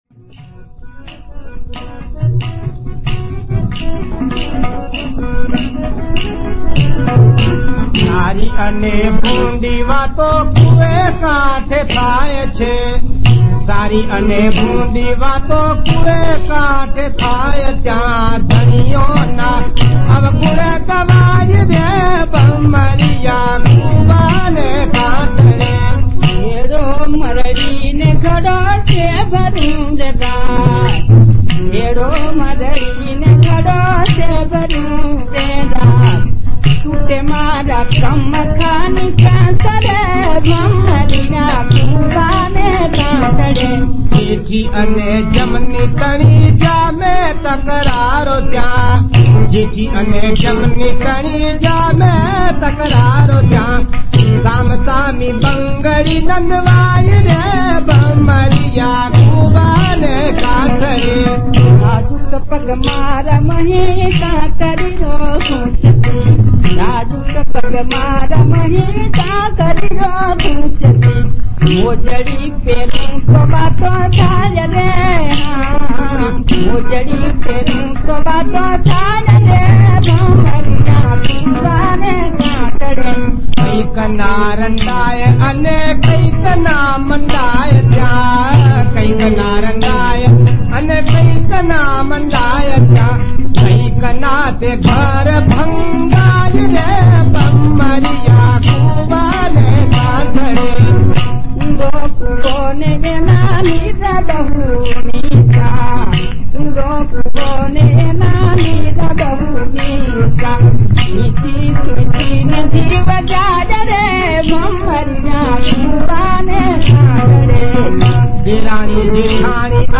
वैकुंठ अने त्रिवेणी नामना पति-पत्नीना पात्रोना कंठे